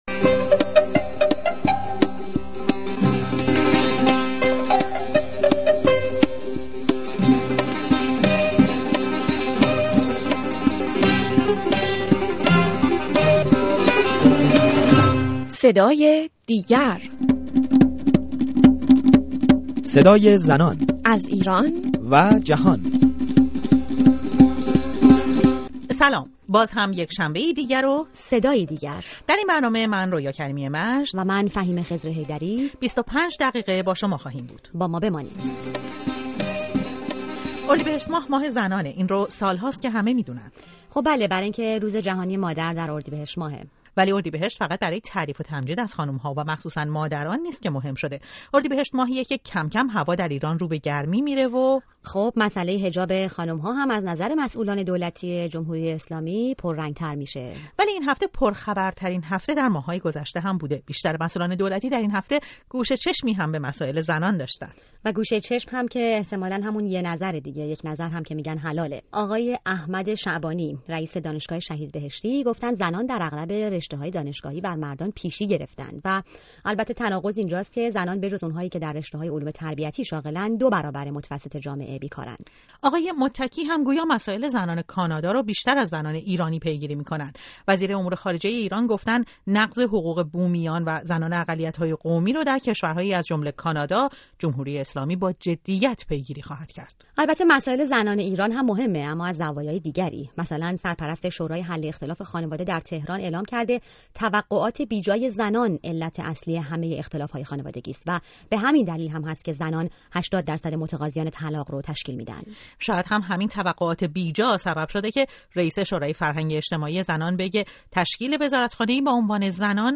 گزارش رادیویی برنامه «صدای دیگر»